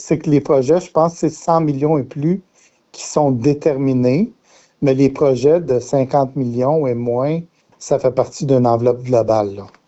Le député de Nicolet-Bécancour a apporté des précisions.